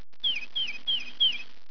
Tufted Titmouse Song File (.WAV)